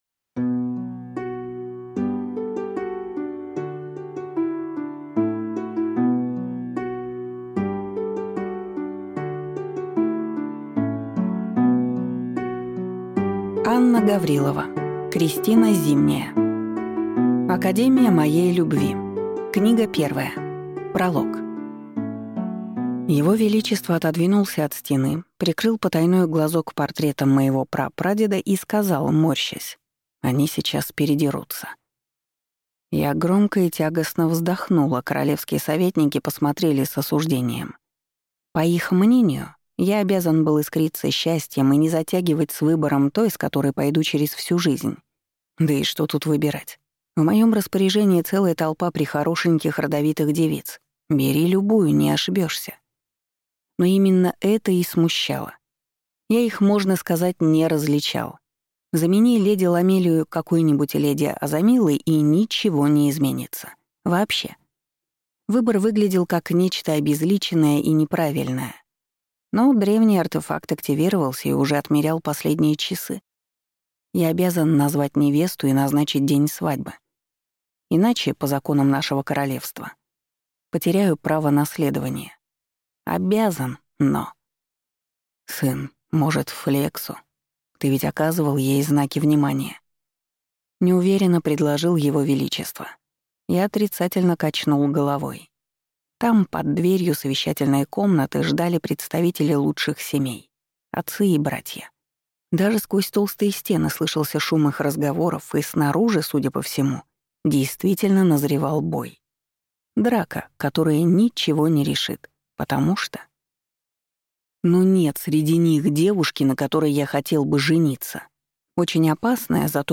Аудиокнига Академия моей любви. Книга 1 | Библиотека аудиокниг